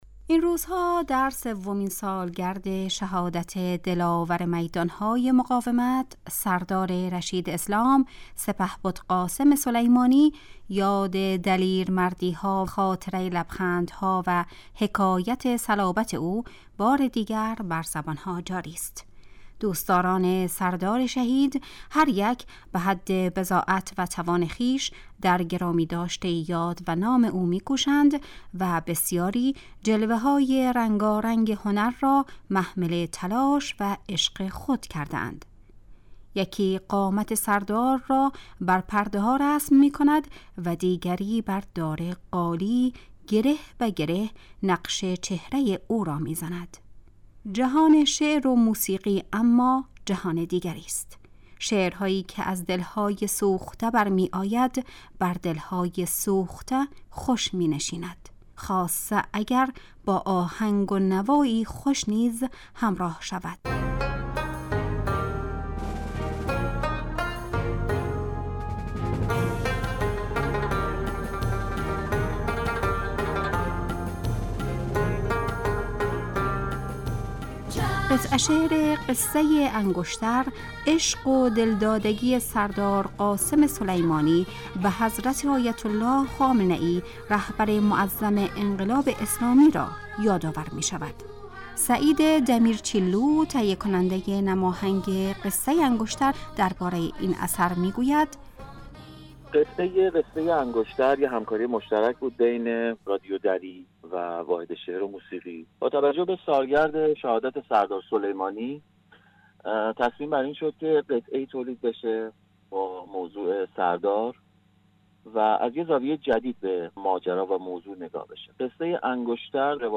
صدای نوجوانان عاشقی که آن را بازخوانی کردند